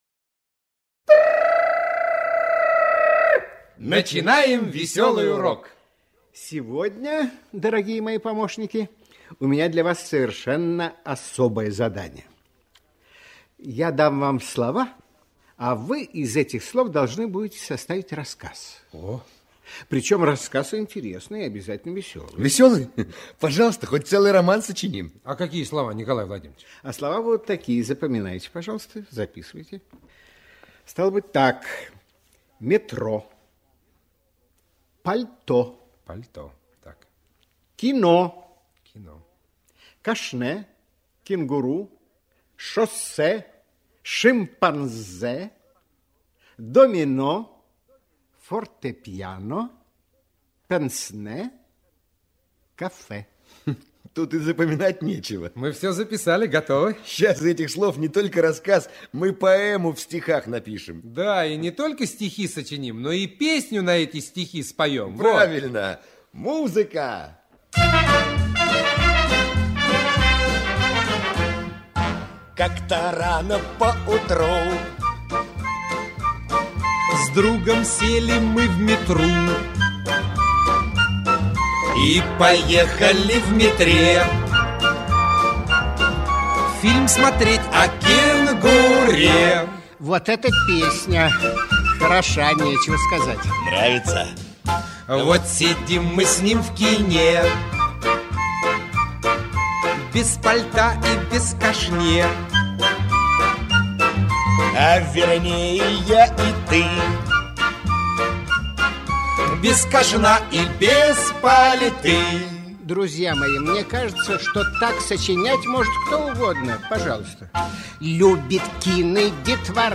В этом разделе размещены аудиоуроки для школьников из образовательной программы «Радионяня», которая транслировалась на всесоюзном радио в 1970-1980 г.
«Весёлые уроки радионяни» в шутливой музыкальной форме помогают детям запомнить правила русского языка..